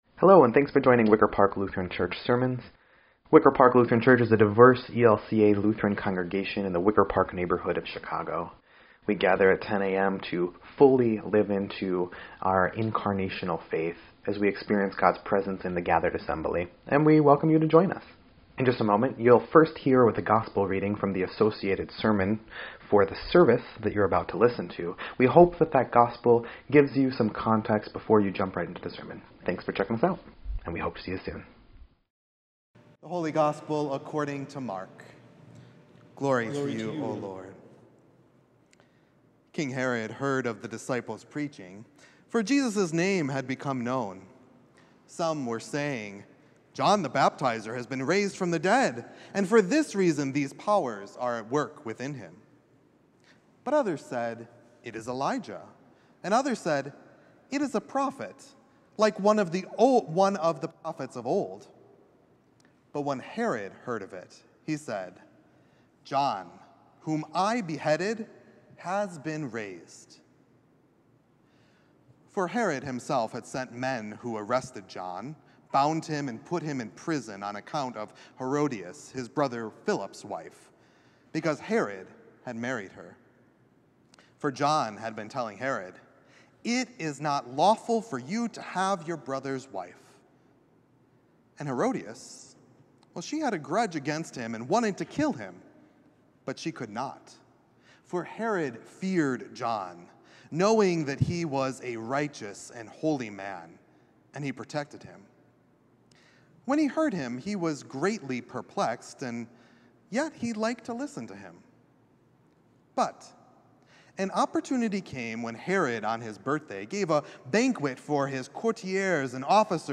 7.14.24-Sermon_EDIT.mp3